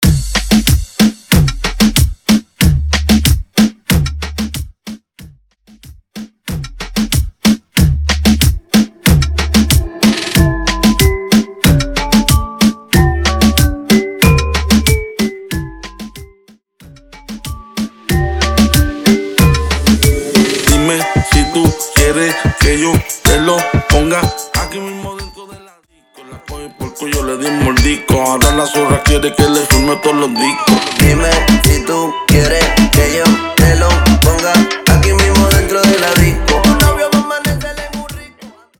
Intro Dirty